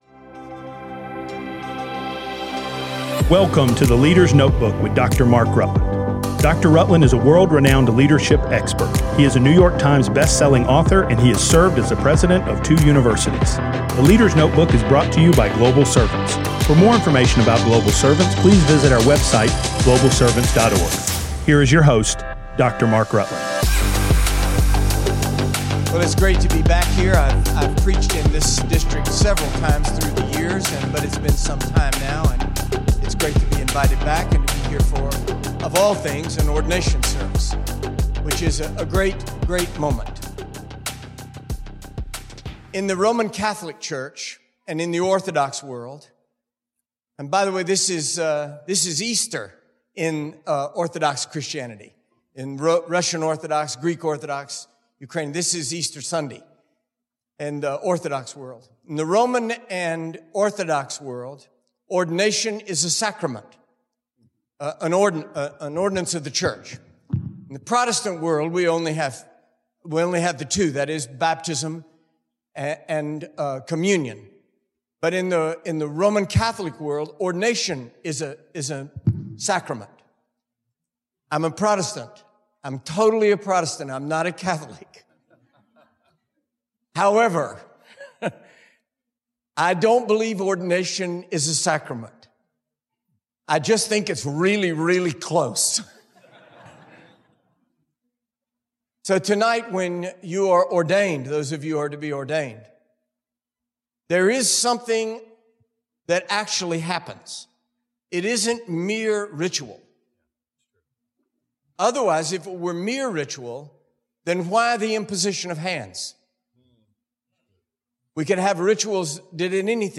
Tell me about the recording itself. speaks to pastors and guests at the Appalachian Ministry Network Conference 2024, held at Life Church Roanoke, Virginia.